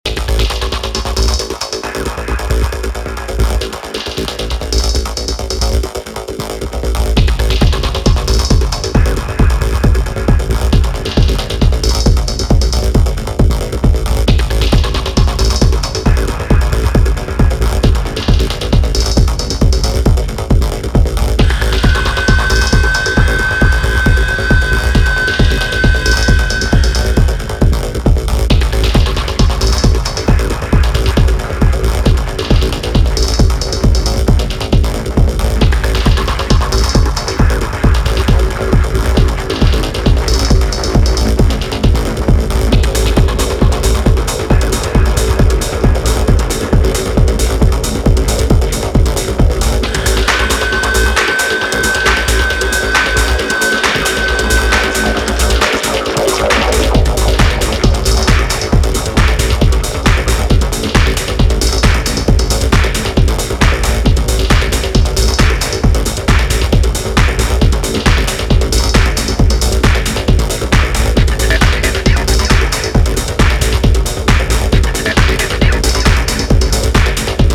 ビキビキと放電するかの如き激しい刺激のシーケンスを特徴とする4トラックス。不吉なサイレンが煽るタイトルトラック